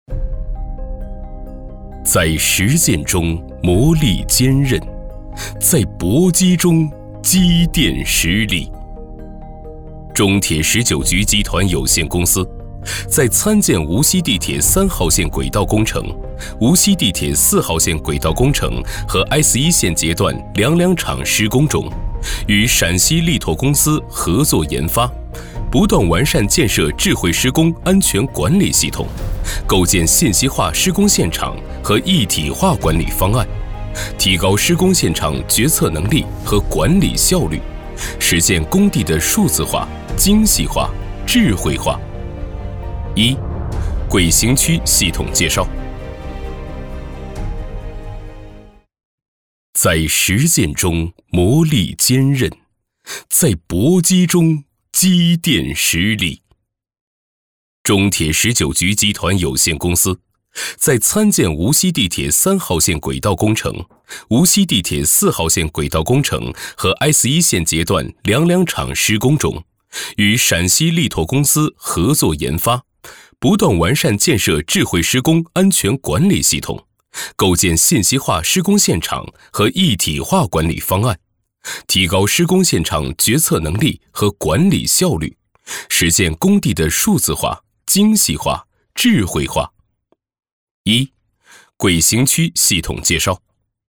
语言：普通话 （31男）
特点：大气浑厚 稳重磁性
风格:浑厚配音
31男--浑厚大气.mp3